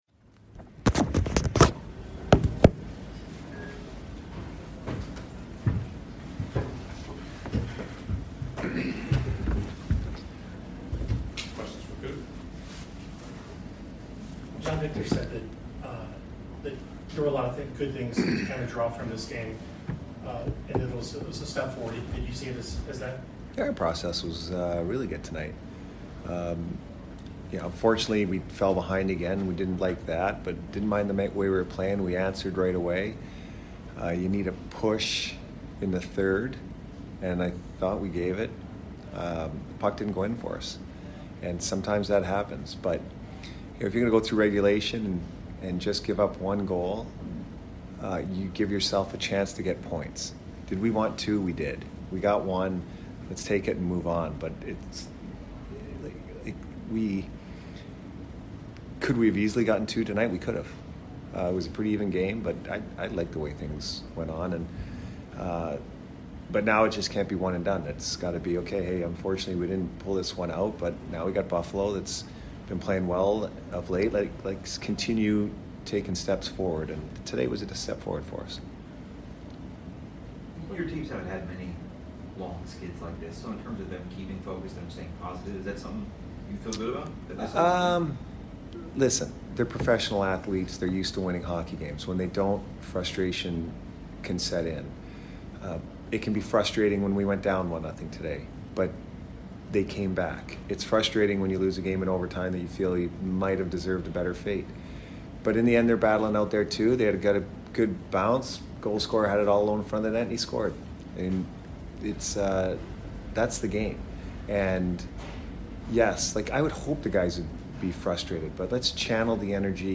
Head Coach Jon Cooper Post Game Vs BOS 4 - 8-2022